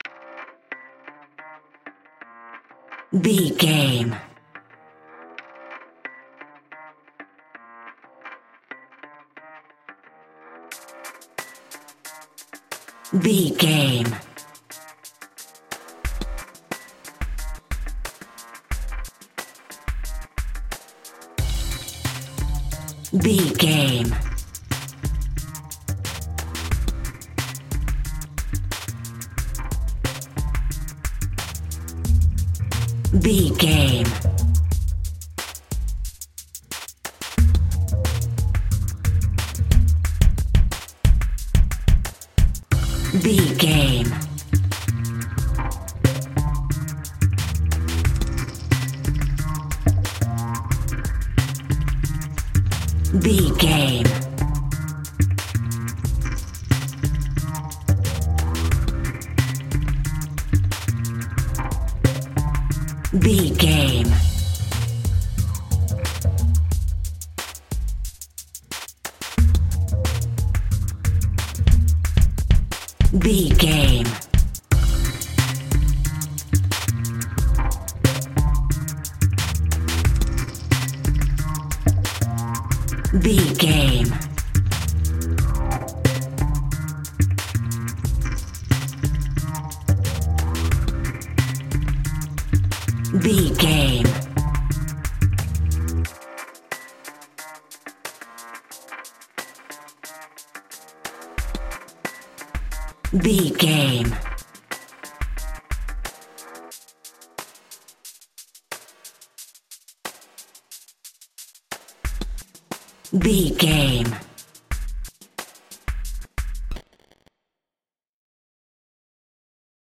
Aeolian/Minor
horror
suspense
ominous
eerie
synths
synth lead
synth bass